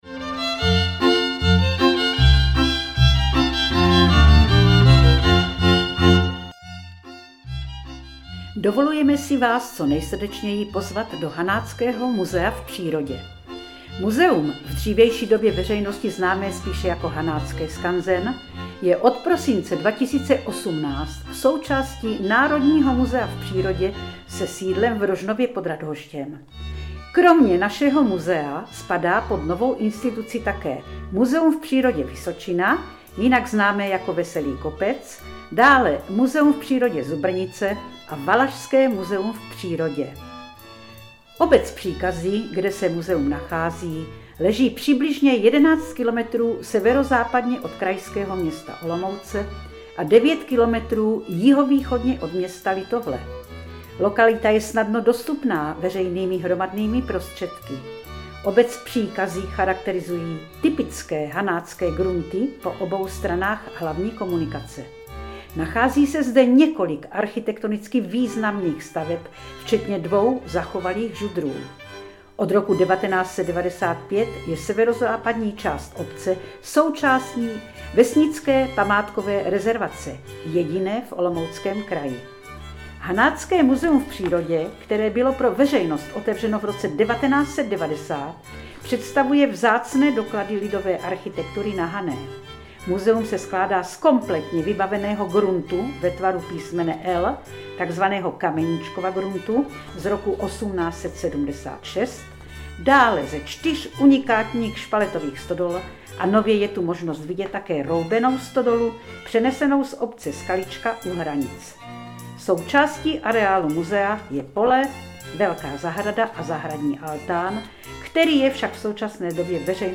Audio pozvánka k přímému poslechu i ke stažení v Mp3
Hudební podkres - Hanácké tance ze 17. století